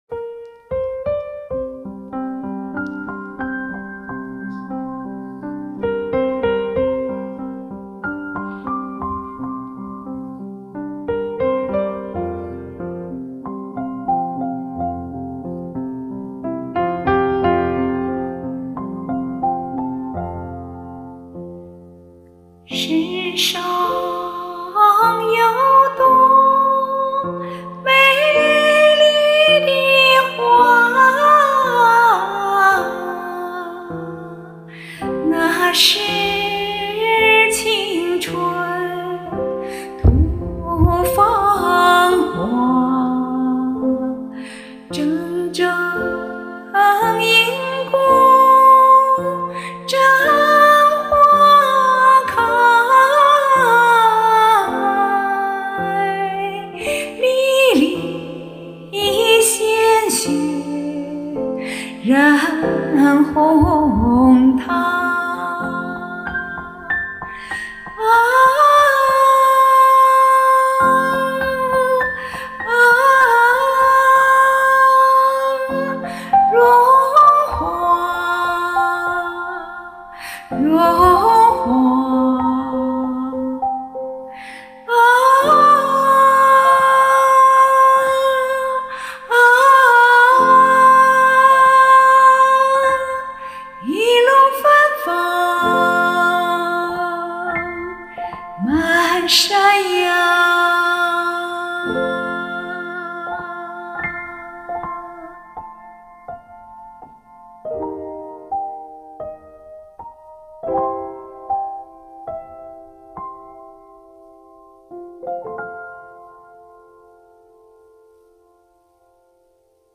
爱极了了淙淙作响的钢琴伴奏！